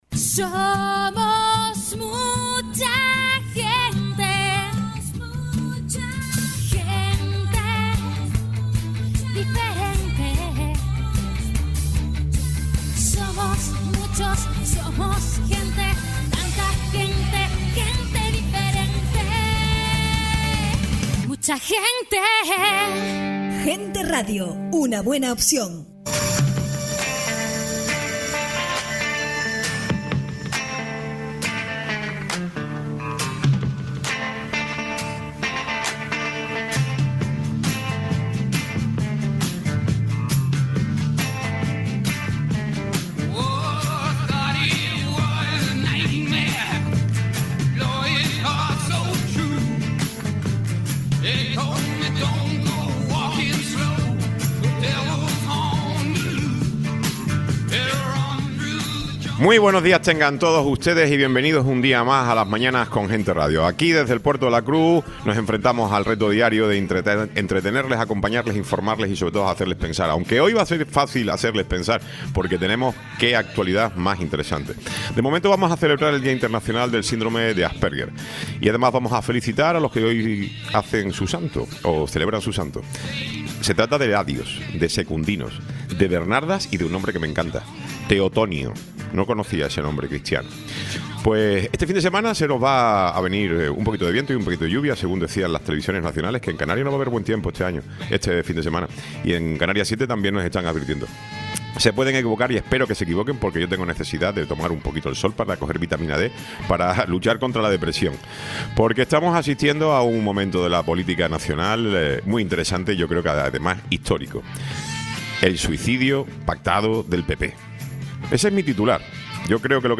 Tiempo de entrevista
Tertulia